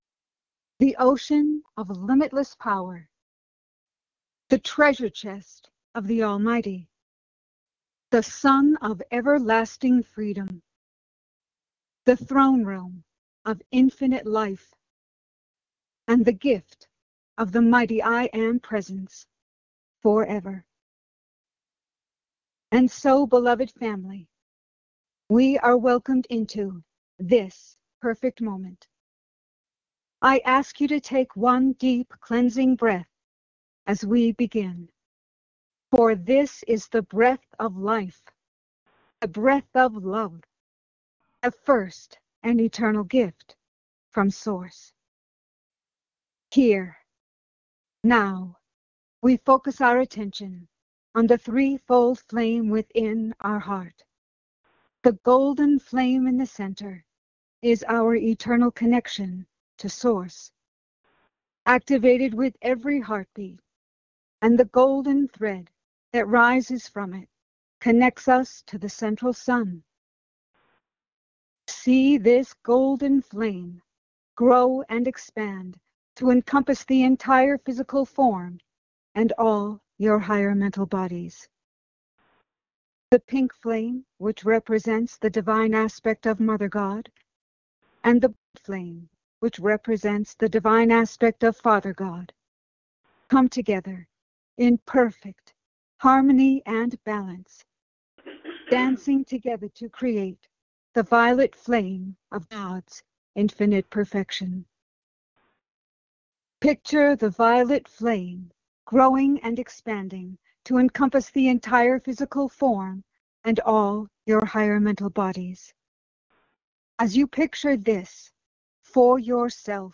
These messages were given during our Ancient Awakenings weekly Sunday conference call in Payson, AZ on March 8, 2026.
Meditation